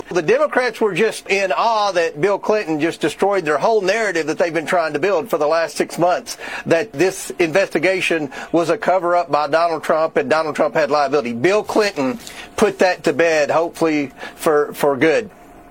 On Fox News, Kentucky Representative James Comer spoke about the much anticipated hearing with the former president.